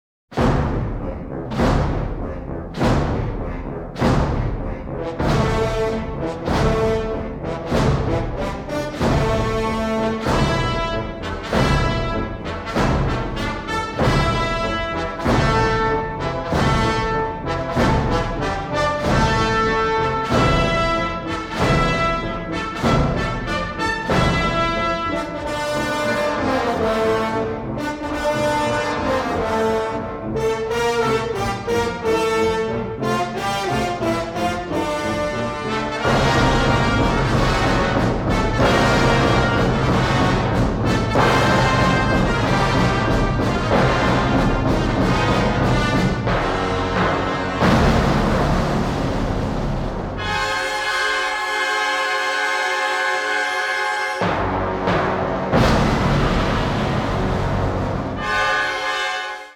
augmented by a large brass and percussion section